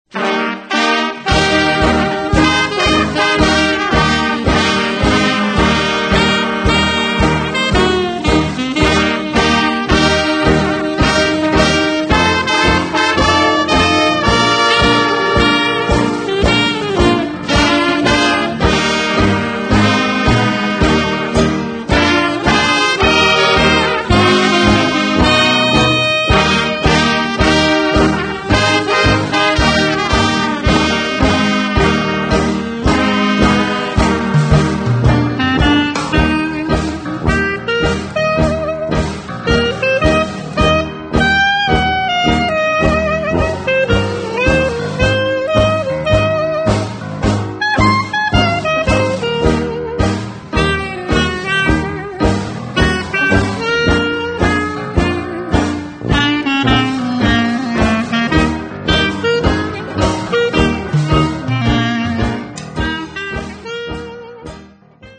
DAS HAMBURGER HOT-JAZZ ORCHESTER